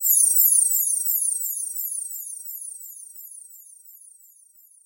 Радио и рингтоны » Звуки » Атмосферные фоны » Звуки звёзд
Откройте для себя удивительные звуки звёзд – космические мелодии, которые перенесут вас в мир фантазий и вселенских тайн. Эта коллекция идеально подходит для расслабления, медитации или просто мечтаний под мерцание далёких светил.
Мелодия звуков звездного света